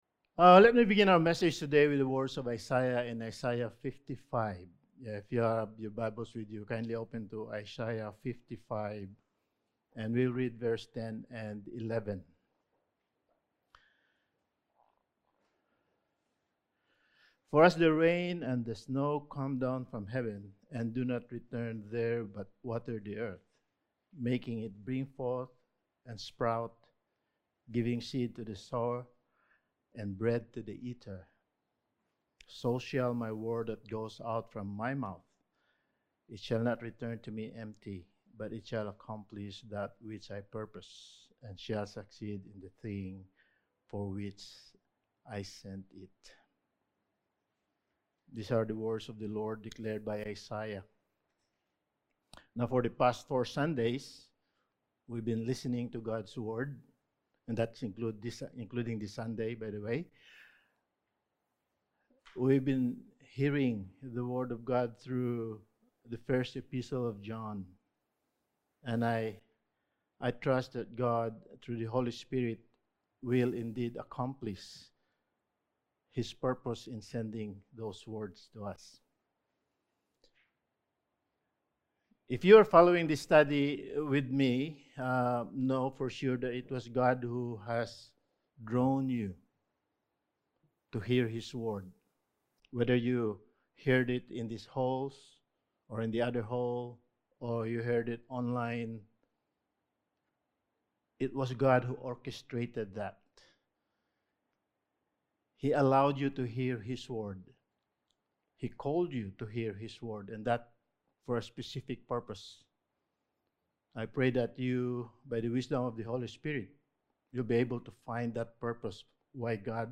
Passage: 1 John 3:4-10 Service Type: Sunday Morning